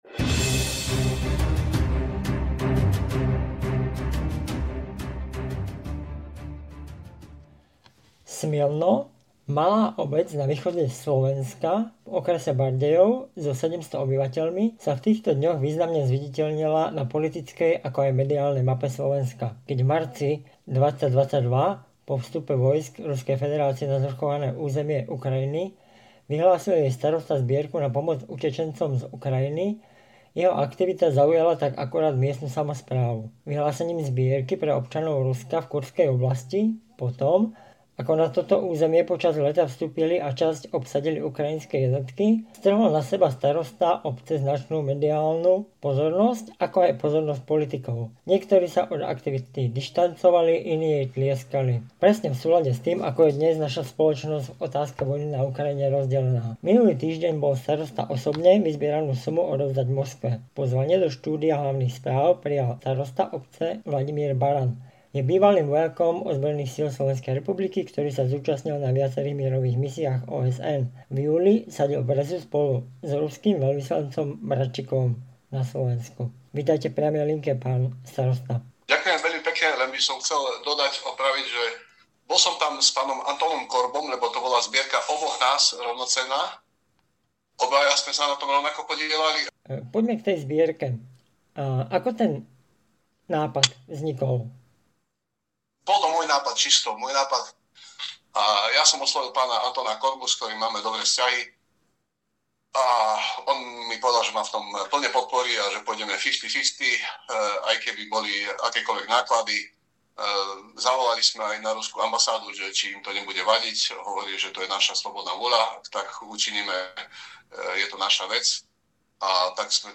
Pozvanie do štúdia Hlavných Správ prijal starosta obce Bc. Vladimír Baran.